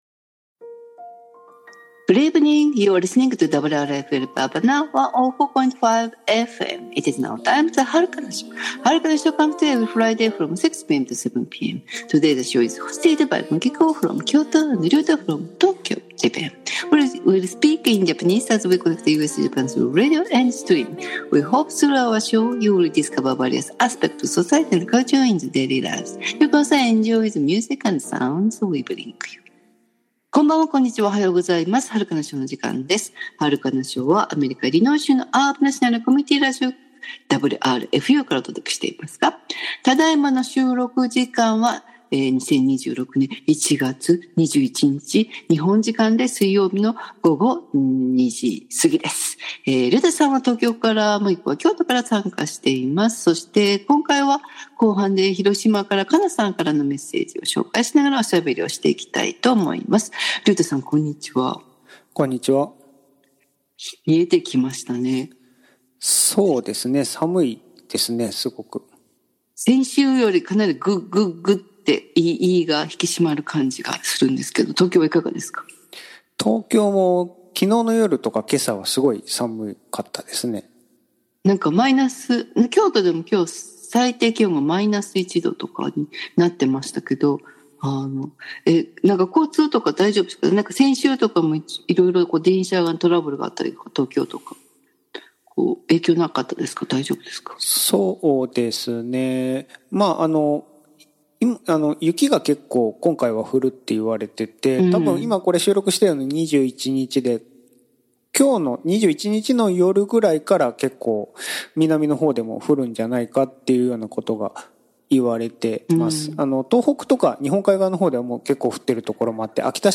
アートで心温まるトークをお楽しみください。